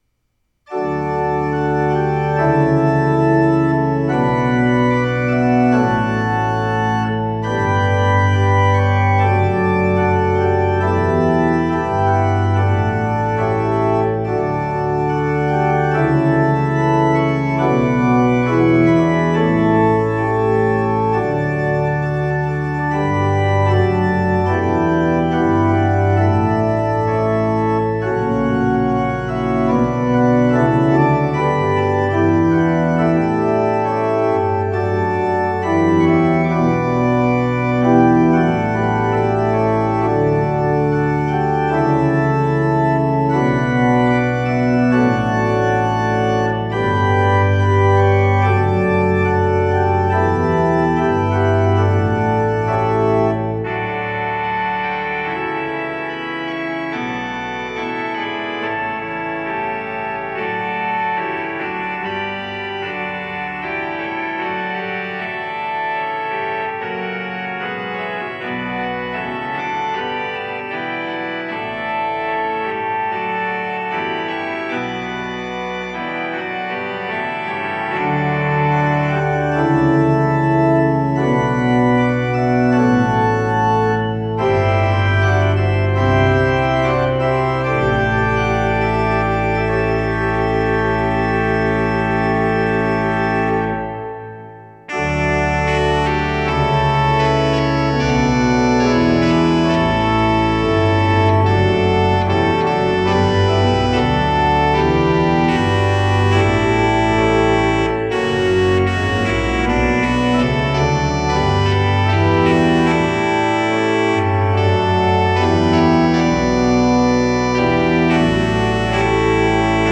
From All That Dwell Below the Skies (Duke Street) – The Organ Is Praise
I’ve tried to keep the majestic nature of the hymn tune in mind for this setting, which was written for a postlude for a recent stake conference. Due to the size of the congregation, I felt that a louder postlude was appropriate.